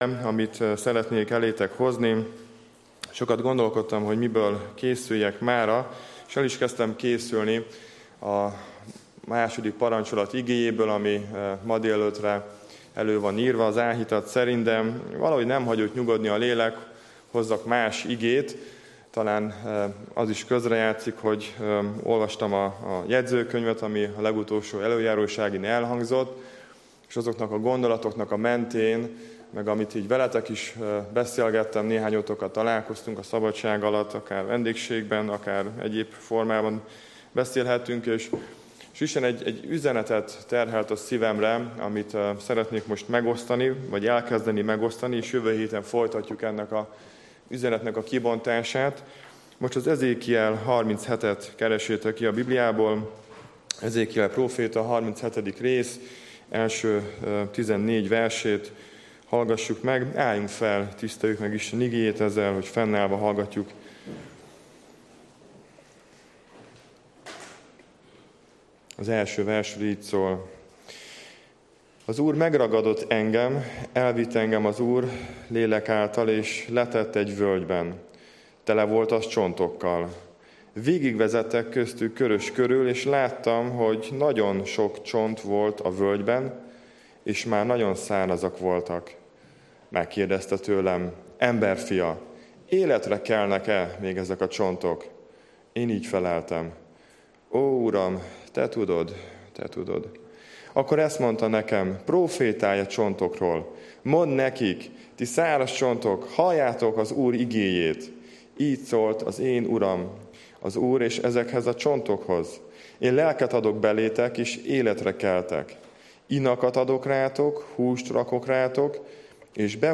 Székesfehérvári Baptista Gyülekezet Igehirdetések